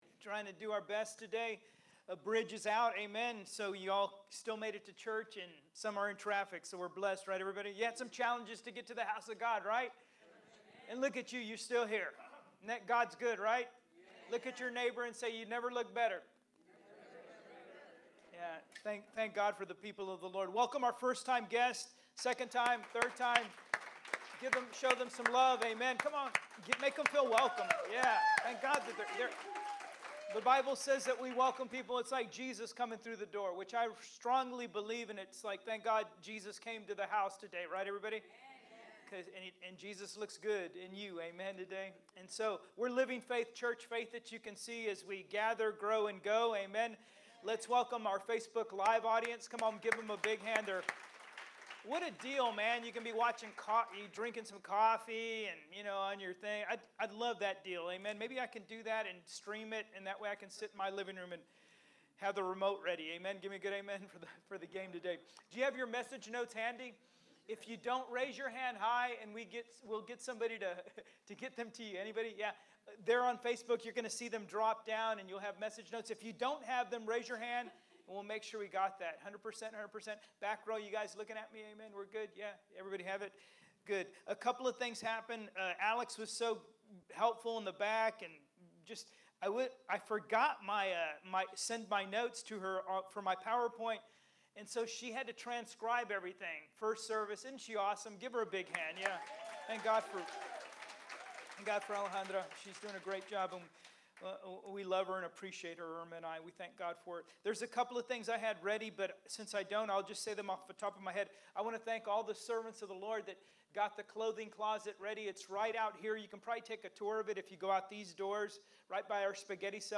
Sermons - Living Faith Church